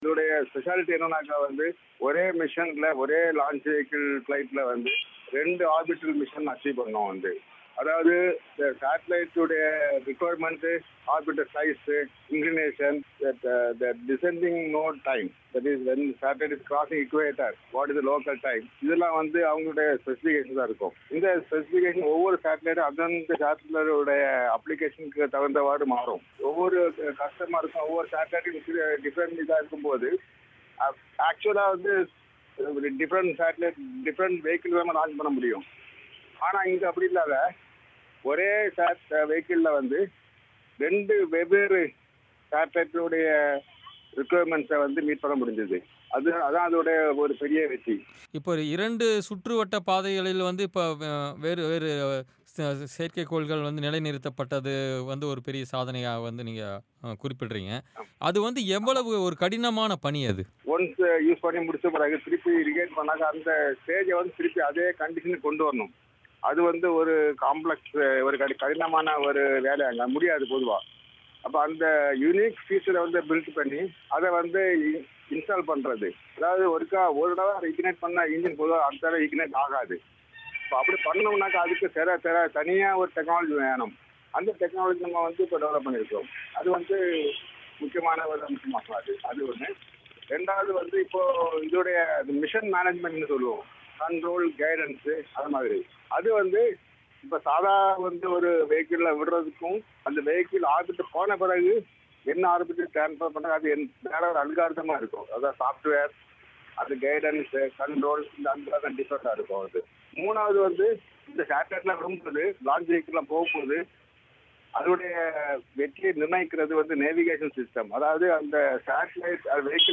விண்வெளி சாதனை: விஞ்ஞானி சிவன் சிறப்புப் பேட்டி
ஒரே ராக்கெட்டில் ஏவப்பட்ட செயற்கைக்கோளை இரு புவிவட்டப் பாதைகளில் நிலைநிறுத்தி, ஐஎஸ்ஆர்ஓ சாதனை புரிந்துள்ளது. இதுகுறித்து, விஞ்ஞானி சிவன், பிபிசி தமிழோசைக்கு அளித்த பேட்டி.